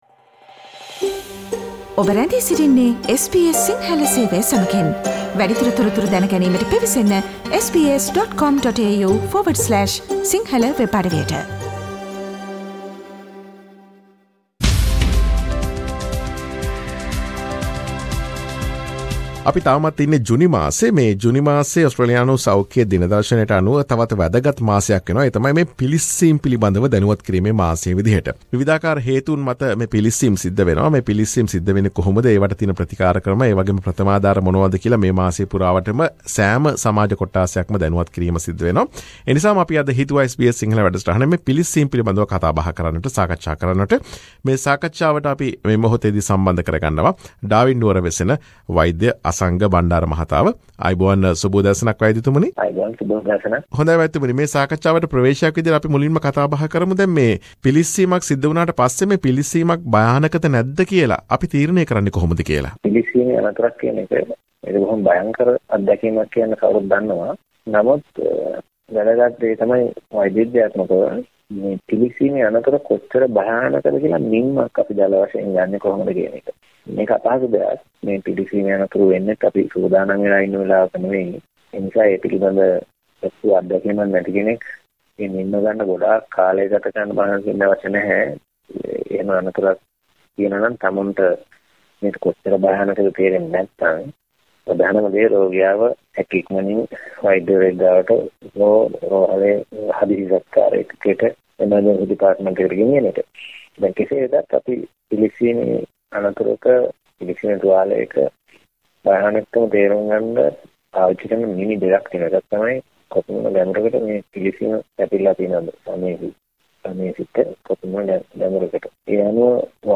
National Burns Awareness Month focuses on enhancing the awareness amongst the Australian community of burns prevention and the correct first aid treatment for burns. Listen to the interview on SBS Sinhala Radio on determining the danger of burns and first aid.